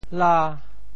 「朥」字用潮州話怎麼說？